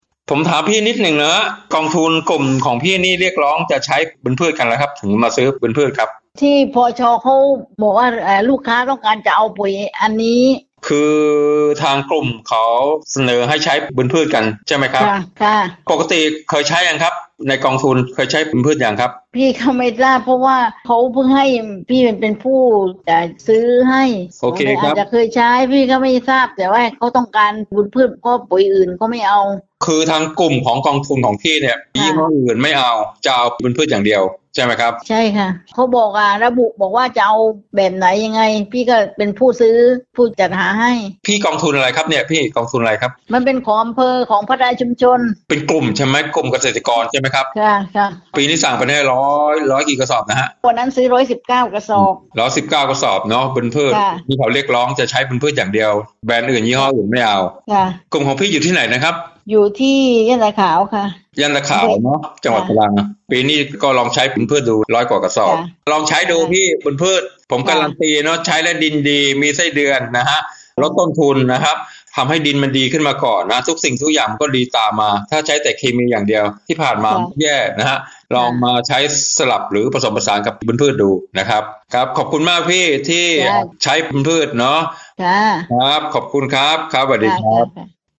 เสียงสัมภาษณ์เกษตรกร